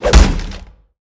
shield-hit-11.mp3